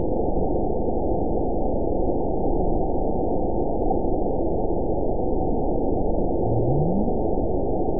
event 919812 date 01/25/24 time 05:06:59 GMT (1 year, 9 months ago) score 9.68 location TSS-AB01 detected by nrw target species NRW annotations +NRW Spectrogram: Frequency (kHz) vs. Time (s) audio not available .wav